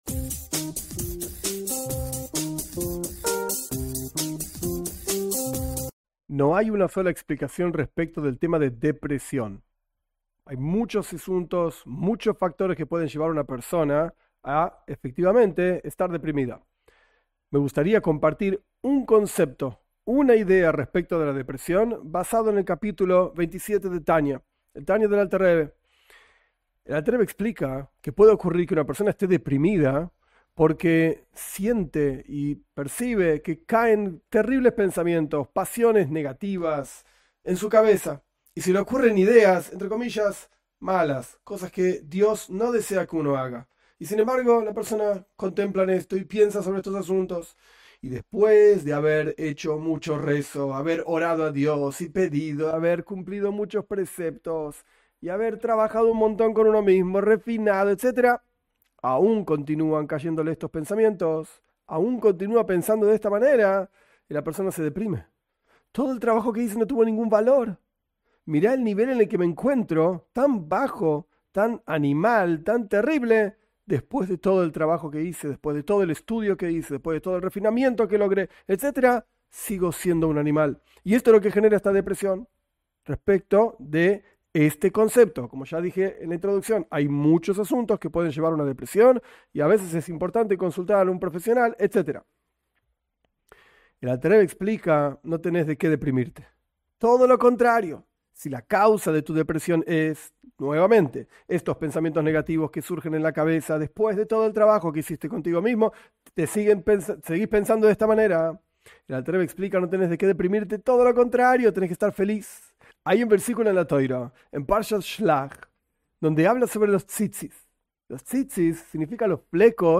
En esta clase corta se explica una herramienta que ofrece el Alter Rebe, en el capítulo 27 de Tania, para luchar contra la depresión.